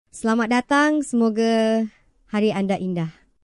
MiniMax Speech 2.5提高了生成音频的相似度和自然韵律度，降低了字错率、减小了AI生成的商务会议、日常对话、英文播客的机械感。